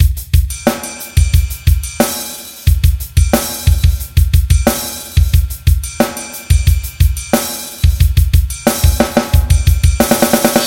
hm drums 001 90bpm
描述：重金属鼓的循环。
Tag: 90 bpm Heavy Metal Loops Drum Loops 1.79 MB wav Key : Unknown Mixcraft